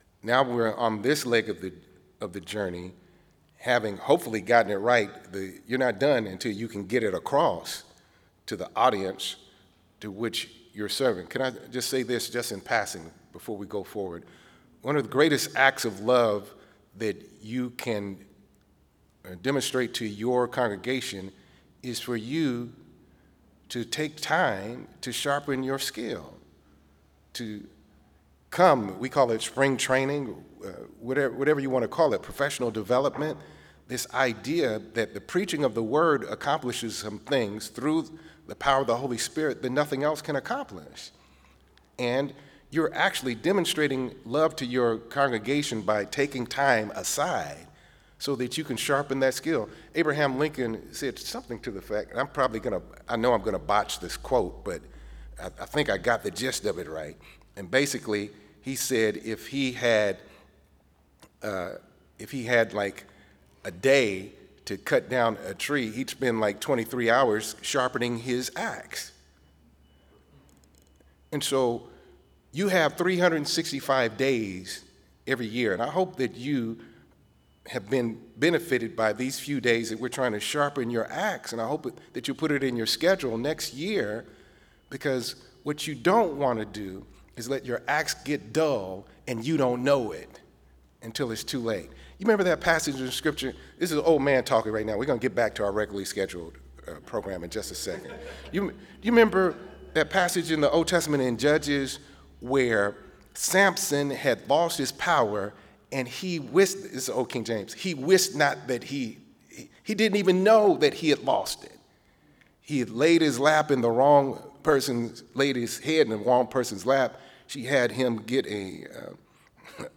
Cape Town 2025 We must not be satisfied to merely get a passage right.